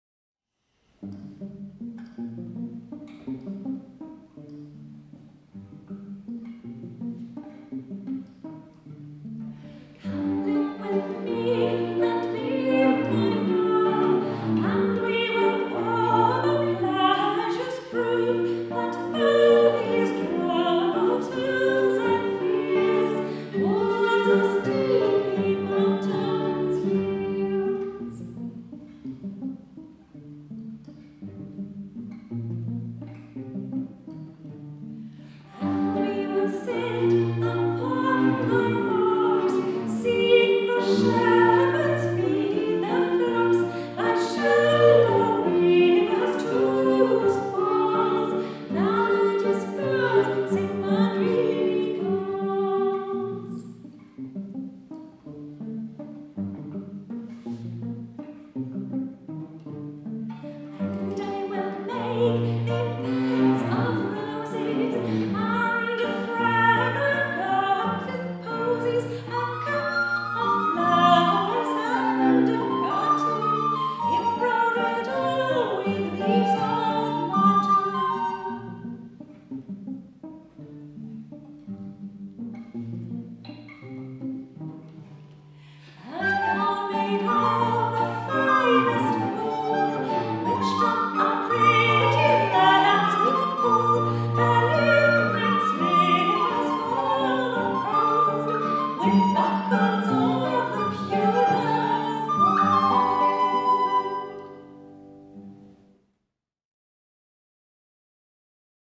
Die Stücke spiegeln höfische Liedkultur des 16. und 17. Jahrhunderts: